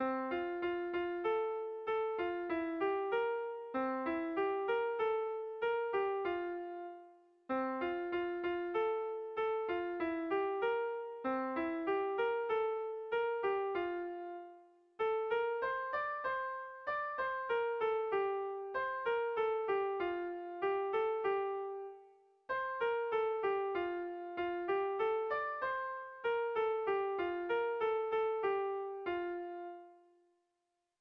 Zuberoa < Basque Country
Zortziko handia (hg) / Lau puntuko handia (ip)
AABD